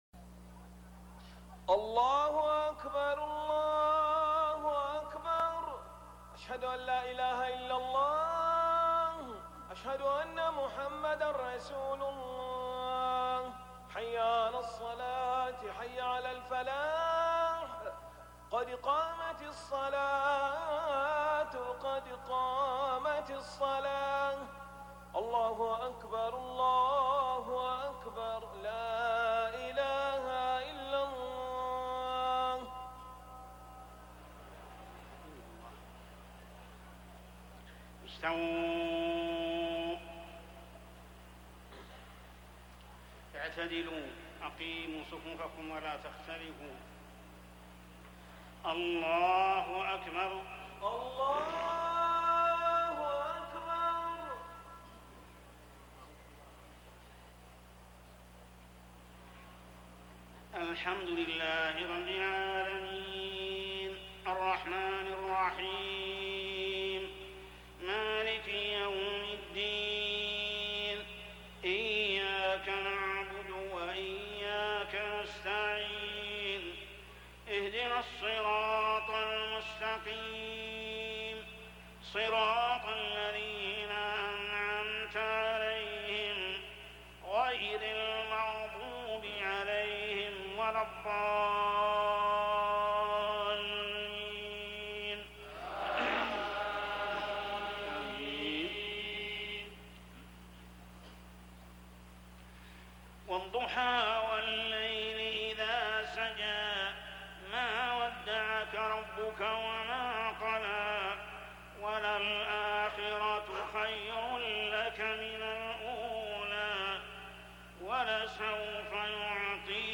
سورتي الضحى والشرح من صلاة العشاء > 1420 🕋 > الفروض - تلاوات الحرمين